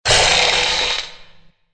traphit_2.ogg